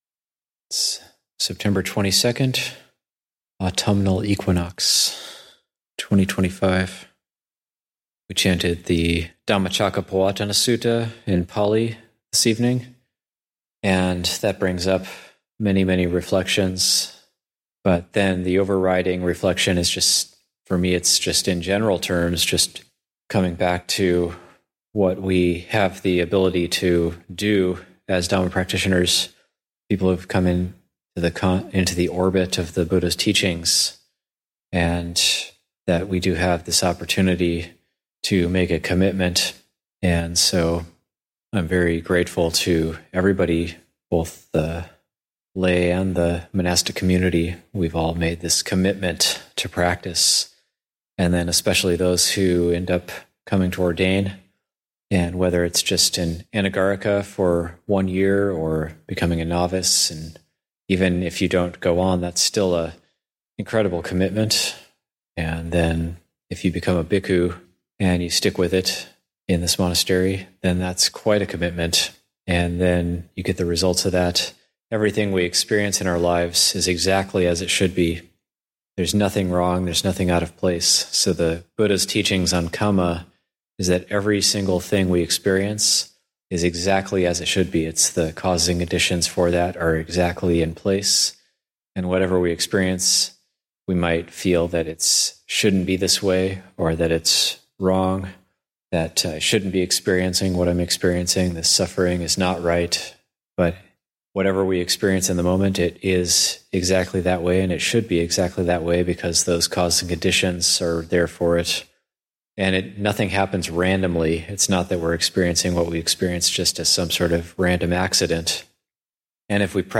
Dhamma Talks given at Abhayagiri Buddhist Monastery.